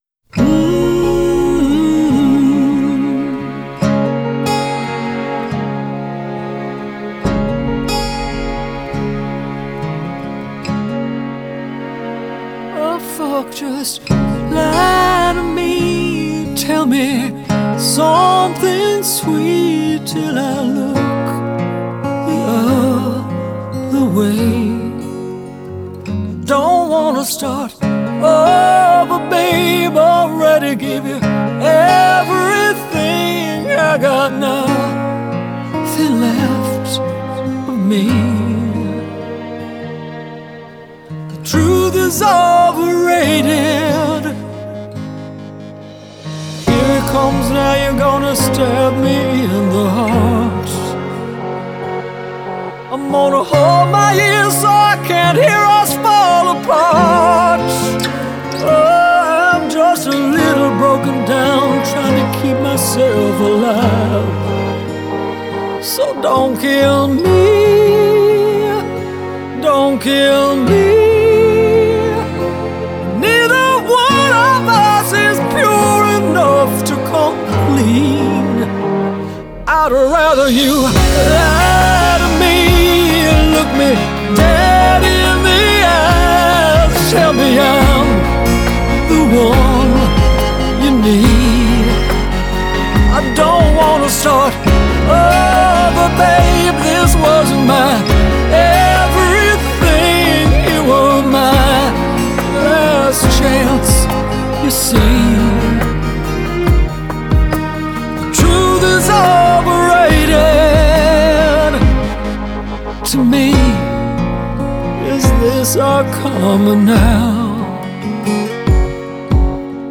장르: Electronic, Pop
스타일: Dance-pop, House, Electro House, Ballad